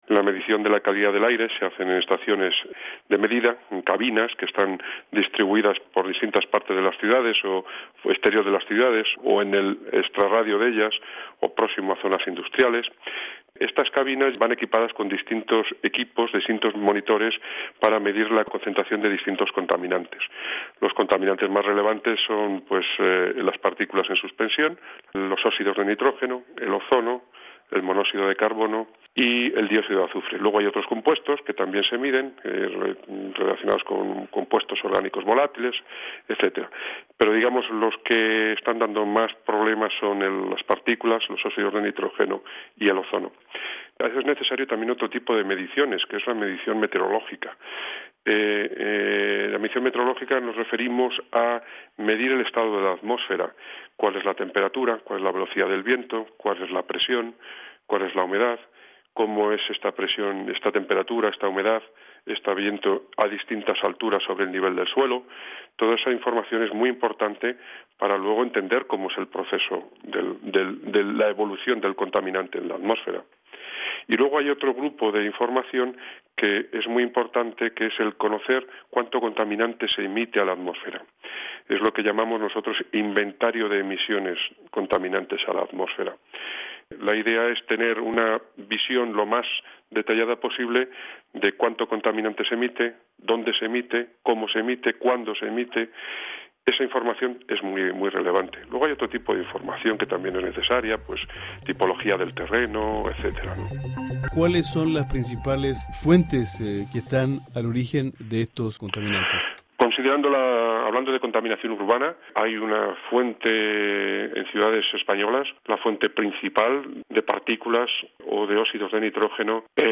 El informe de Radio Francia Internacional explica cómo se miden los niveles de contaminación y cuáles son sus riesgos para la salud.